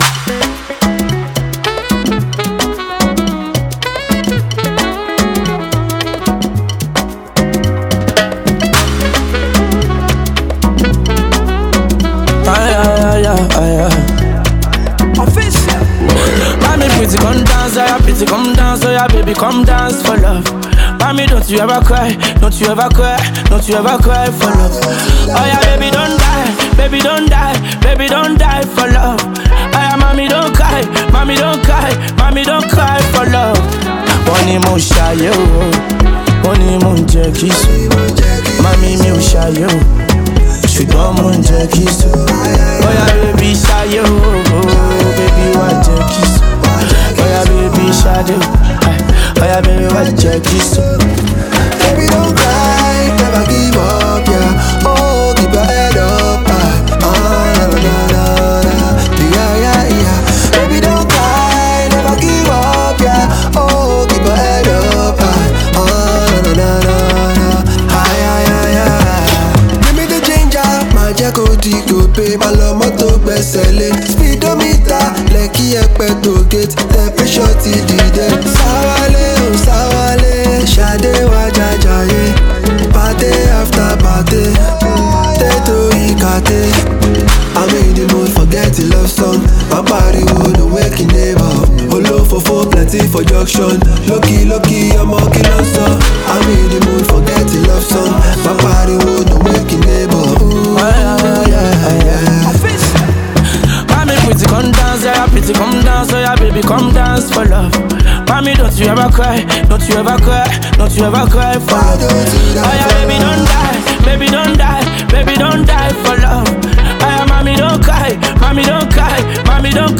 Naija Afrobeat